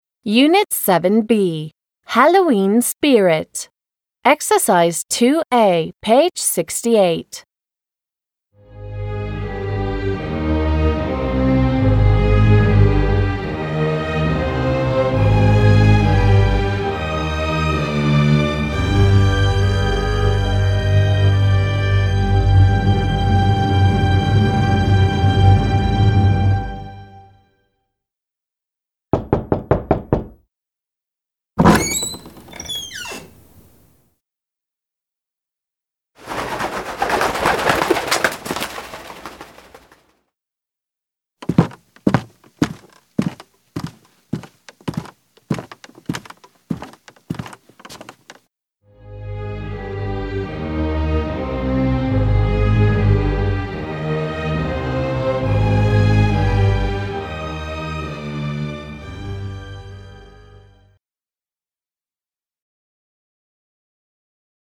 2. a) Listen to the sounds and look at the pictures.